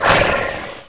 growl1.wav